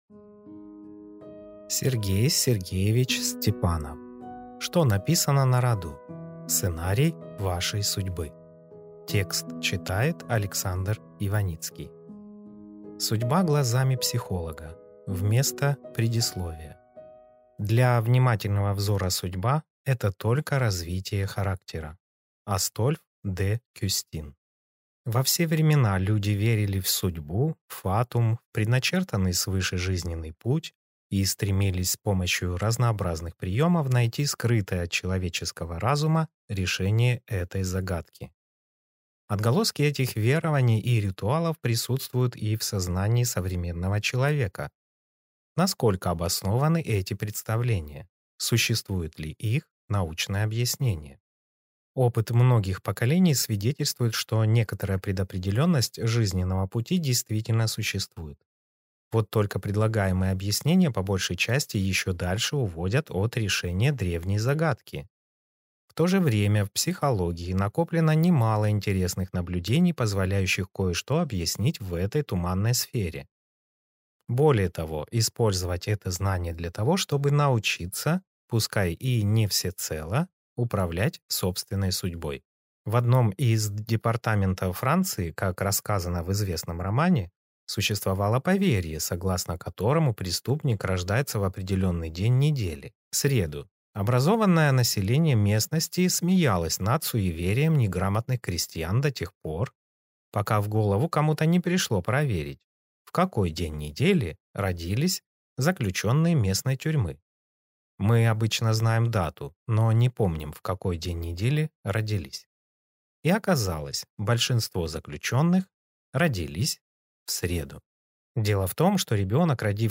Аудиокнига Что написано на роду? Сценарий вашей судьбы | Библиотека аудиокниг